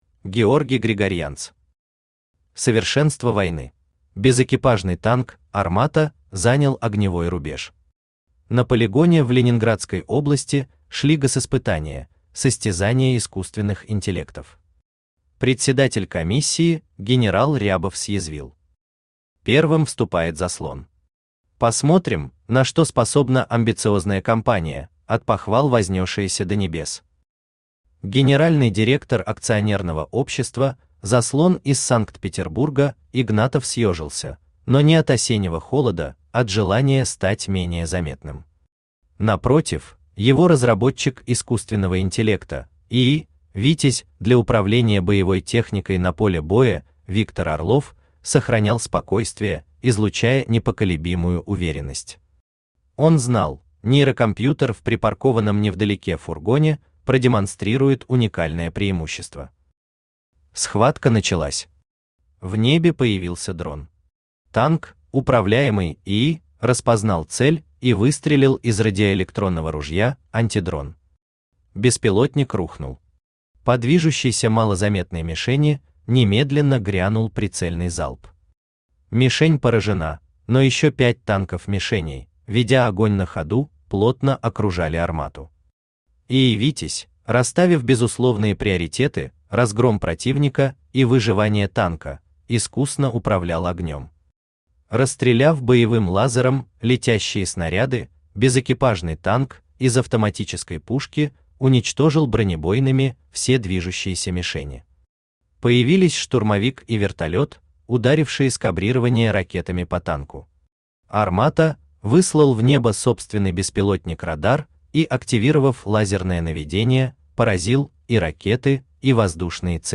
Аудиокнига Совершенство войны | Библиотека аудиокниг
Aудиокнига Совершенство войны Автор Георгий Григорьянц Читает аудиокнигу Авточтец ЛитРес.